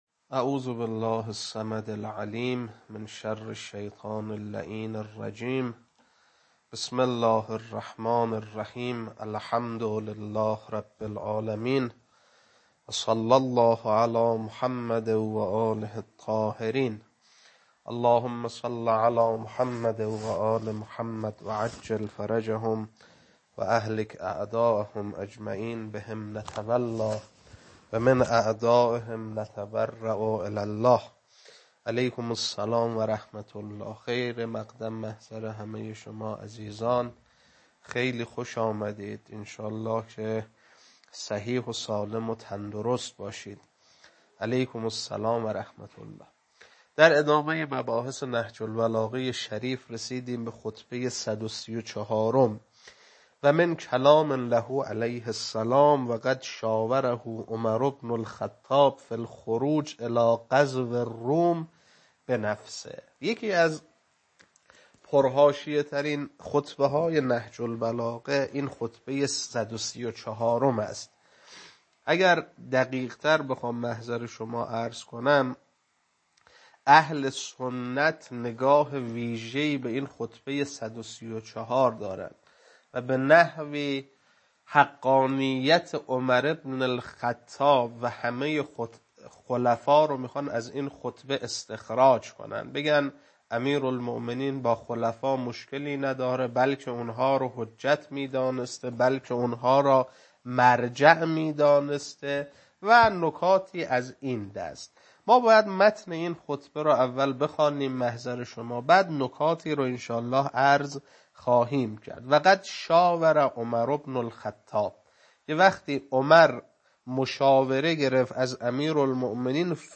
خطبه-134.mp3